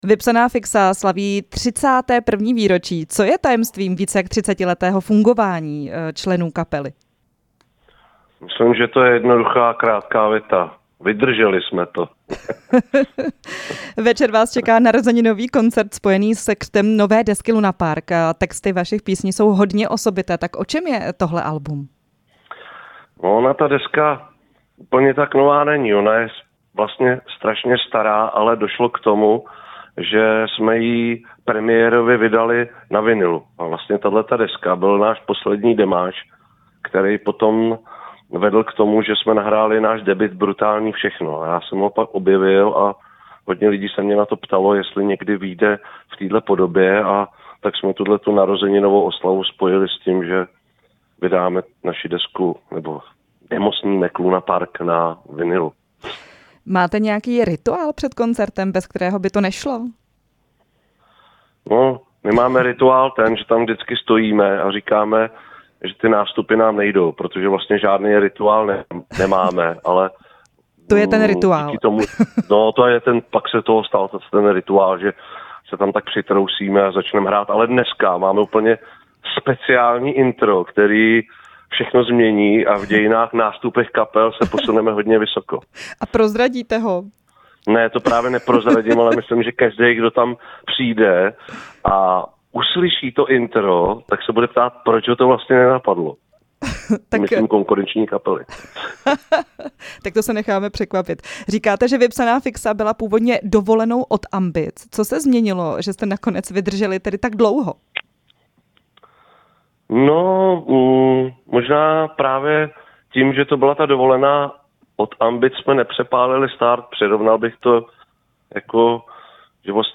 Vypsaná fiXa dnes slaví 31. let na scéně koncertem v pražském Areálu 7. Hostem vysílání RP byl frontman kapely Michal Mareda, známý jako Márdi.
Rozhovor s frontmanem kapely Vypsaná fiXa Márdim